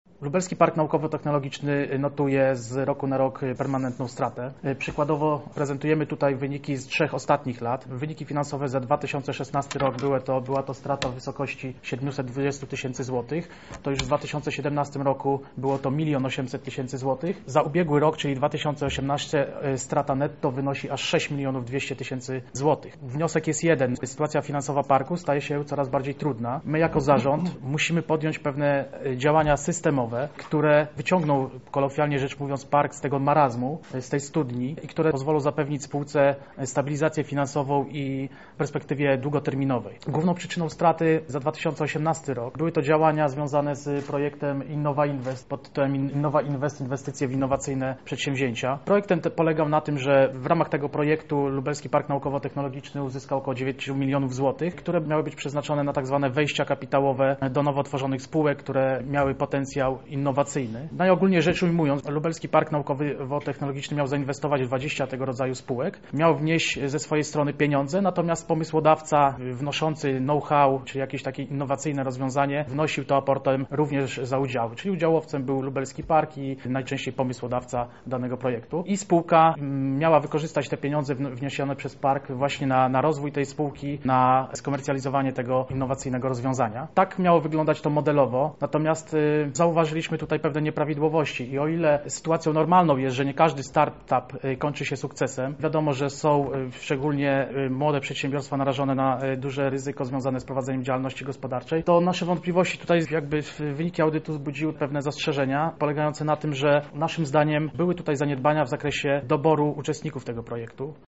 Na konferencji zorganizowanej o godzinie 12 przedstawione koncepcje utworzenia w Lublinie czegoś, co niejednemu przypomni warszawskie Centrum Nauki Kopernik.